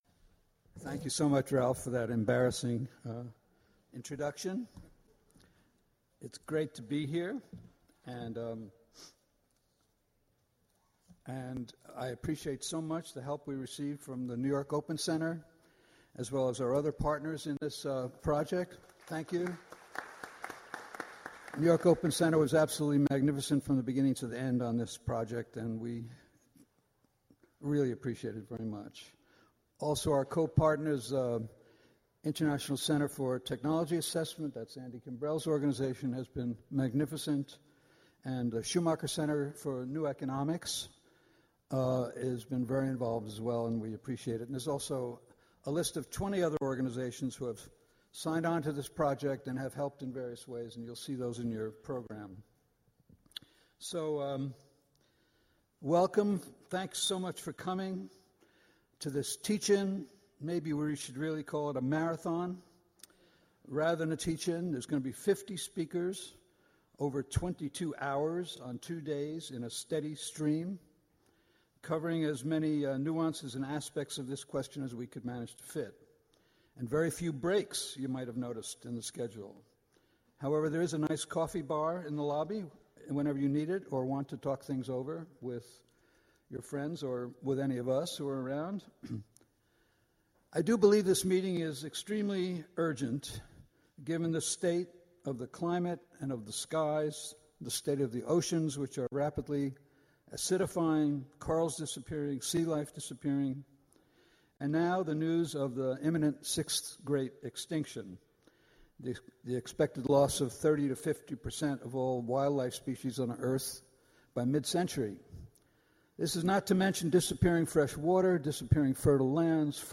This presentation of Jerry Mander was recorded at the International Forum on Globalization ’s Techno-Utopianism & The Fate Of The Earth Teach-In held in New York City on October 25-26, 2014.